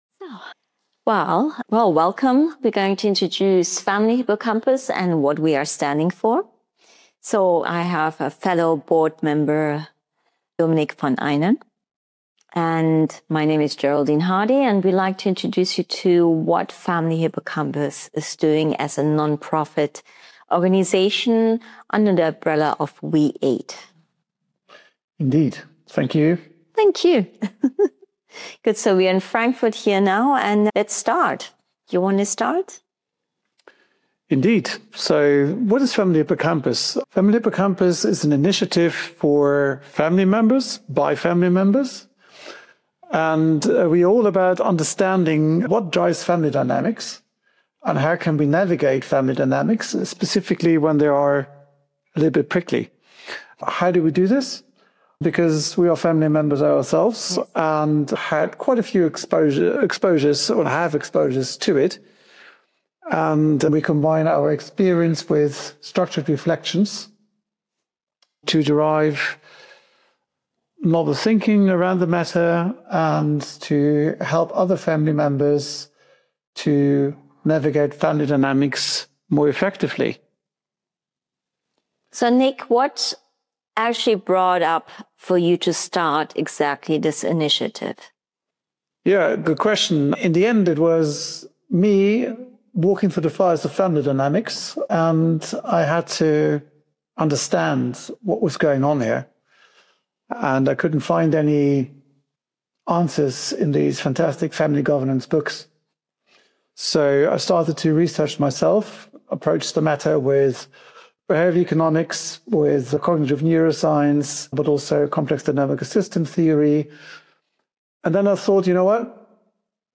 peer-to-peer conversation